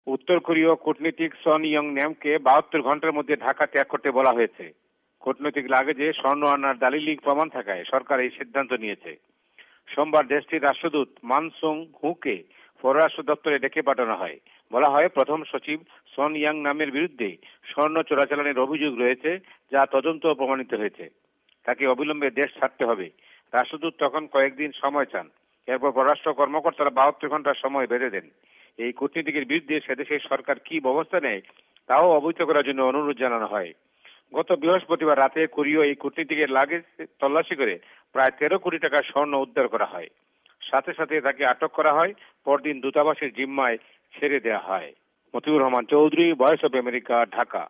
ভয়েস অফ এ্যামেরিকার বাংলাদেশ সংবাদদাতাদের রিপোর্ট